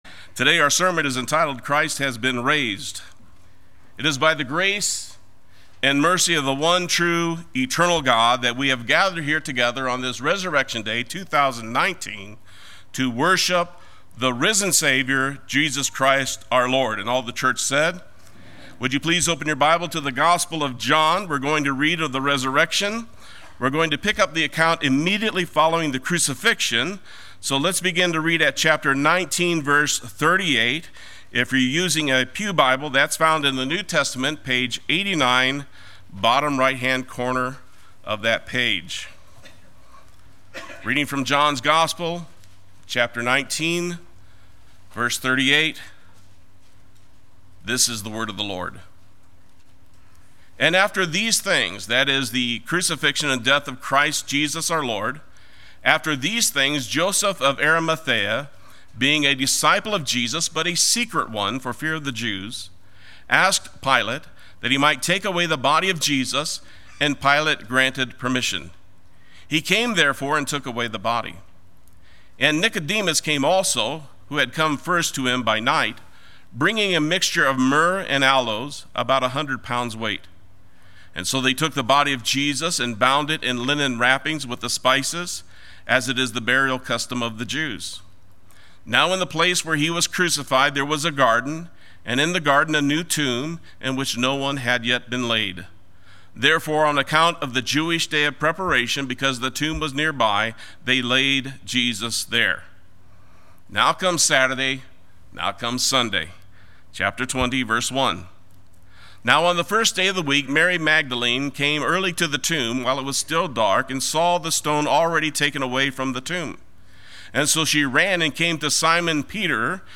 Play Sermon Get HCF Teaching Automatically.
“Christ Has Been Raised” Sunday Worship